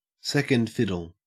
Ääntäminen
Ääntäminen AU